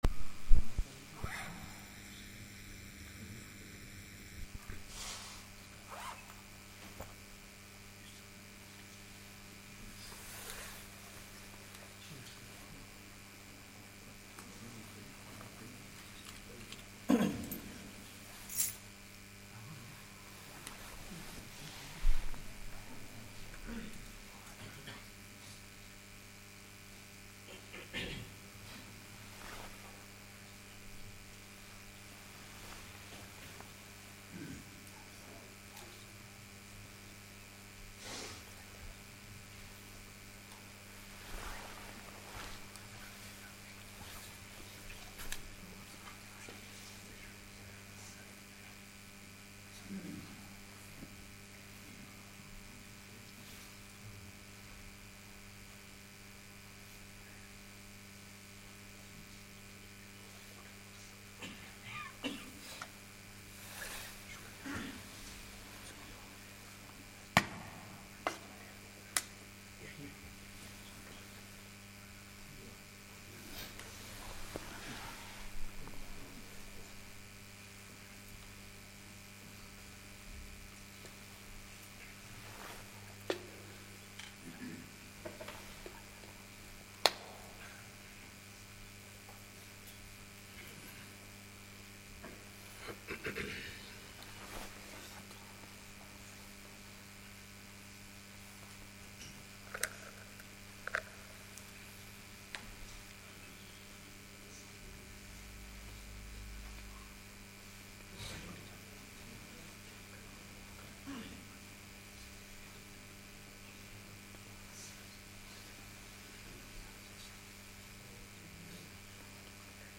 Jumuah